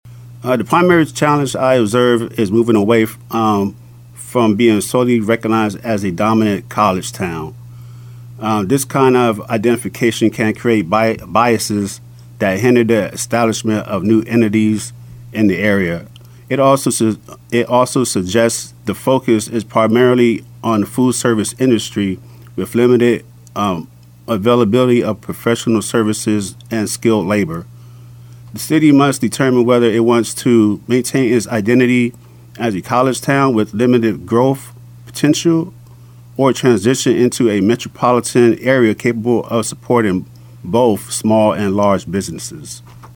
News Radio KMAN has invited candidates seeking a seat on the Manhattan City Commission and Manhattan-Ogden USD 383 school board to be interviewed ahead of Election Day.